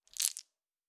Fantasy Interface Sounds
Dice Shake 5.wav